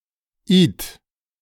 The Ith (German: [iːt]